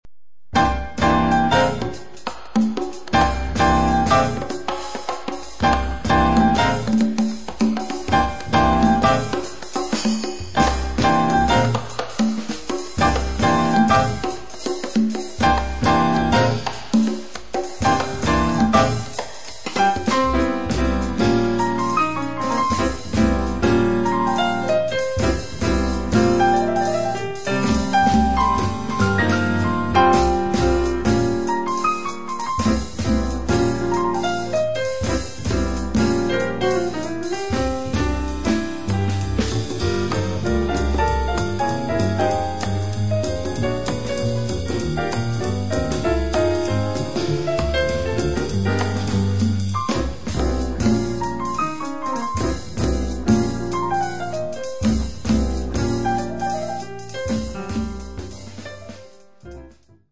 piano, vibes
bass
drums
bongos & congas
violin
ukulele
offrono un modello di vivace gradevolezza